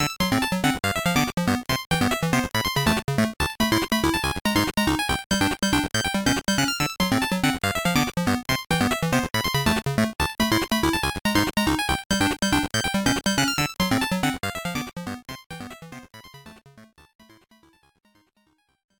Trimmed to 30 seconds and applied fade-out when needed